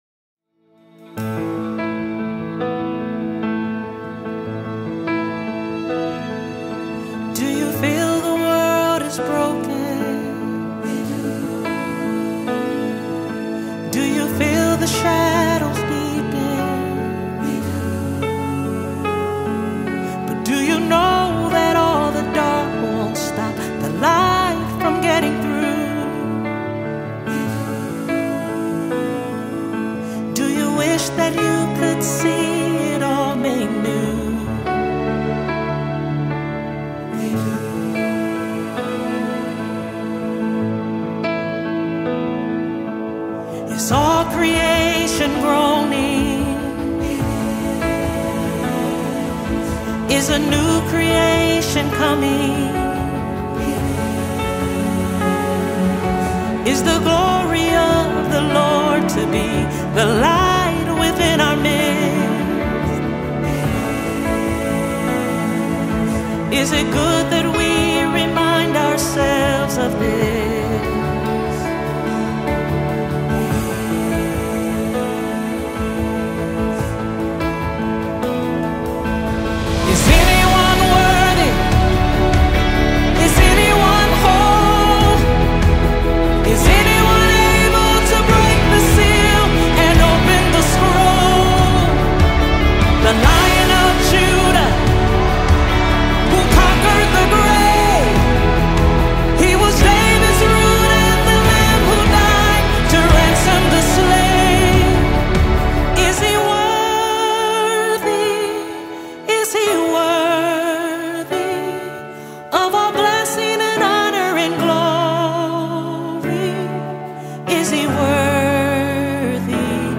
A Soul-Stirring Worship Anthem
worship song
With her powerful vocals and deep spiritual insight